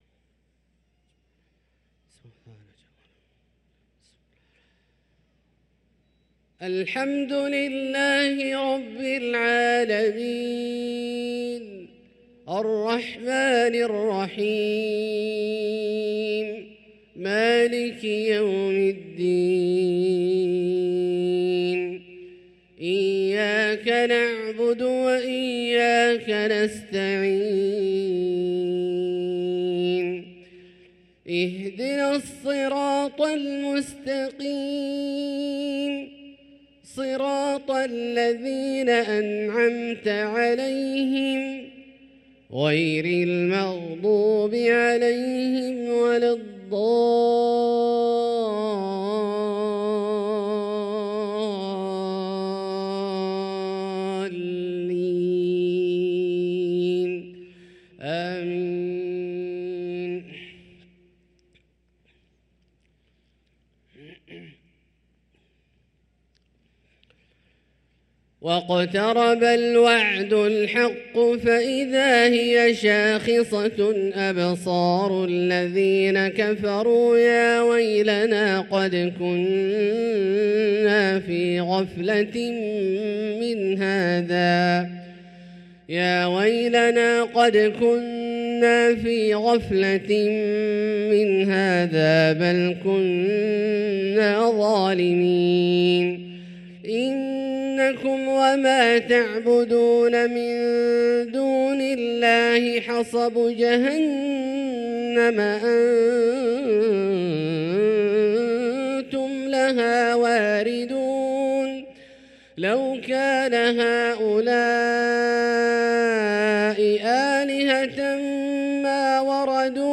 صلاة العشاء للقارئ عبدالله الجهني 25 ربيع الآخر 1445 هـ